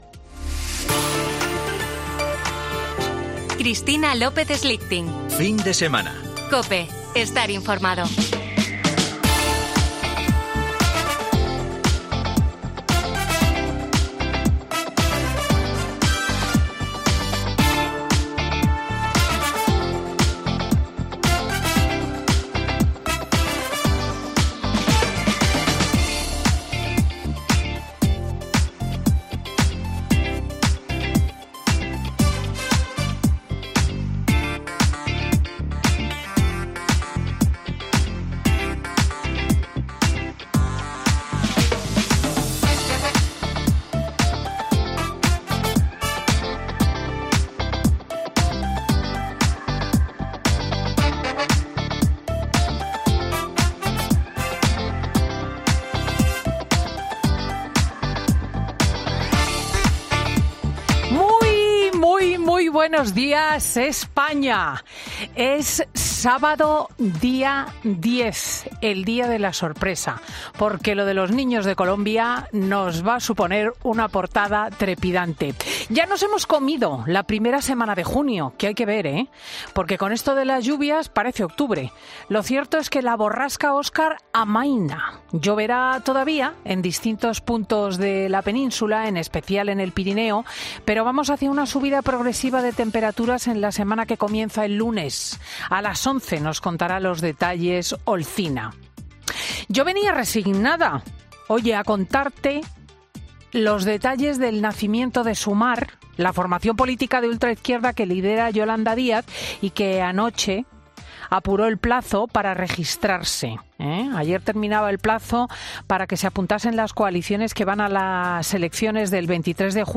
Cristina López Schlichting centra su editorial de este sábado en Fin de Semana de COPE en el gran milagro del día, la localización de los cuatro hermanos desaparecidos en la selva colombiana tras un accidente aéreo, sin pasar por alto, la política de casa centrada en las elecciones del 23 de julio: